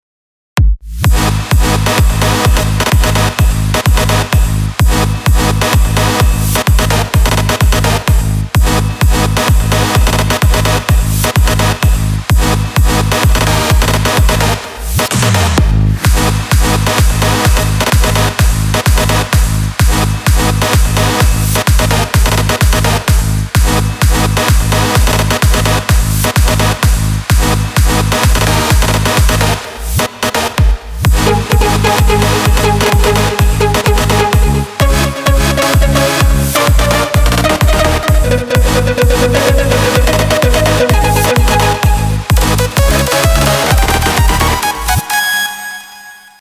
שים לב שבקובץ A1 יש “נפילה” בווליום כשמגיע הדרופ - ממליץ שתגביה אותו קצת.